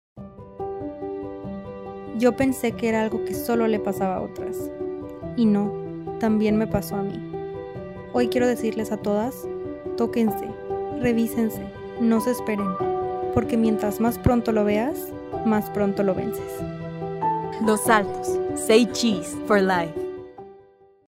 Historias reales de mujeres reales
Los nombres y voces utilizados son ficticios, con el objetivo de transmitir mensajes universales de prevención y esperanza.
testimonial-4-v2.mp3